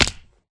fallsmall.ogg